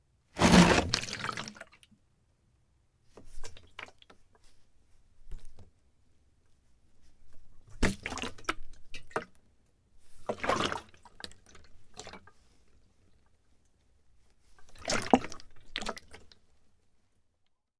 随机 "塑料气体容器满载重拾刮擦和荡涤干燥的房间1
描述：塑料气体容器全重皮卡刮和晃动干燥room1.wav
Tag: 晃动 拾取 塑料 刮去 气体 容器 充满 干燥